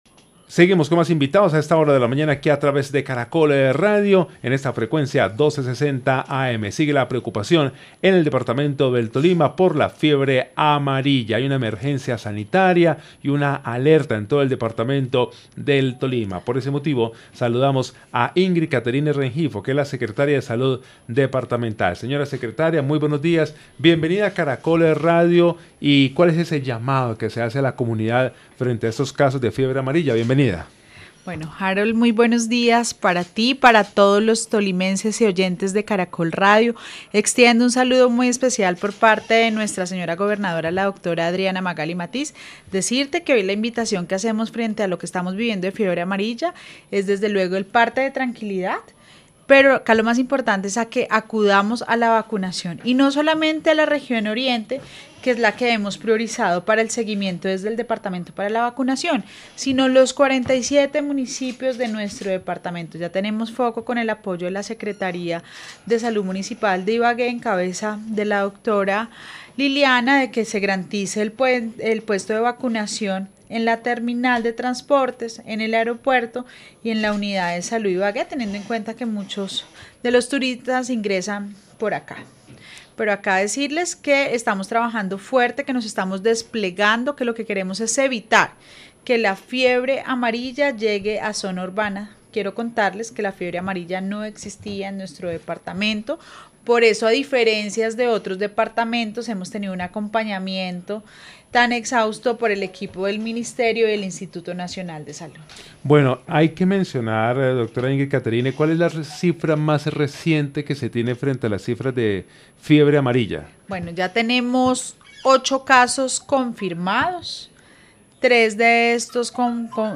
Reviva la entrevista con la secretaria de salud departamental Ingrid Katherine Rengifo en Caracol Radio Ibagué.
Ingrid Katherine Rengifo, secretaria de salud del Tolima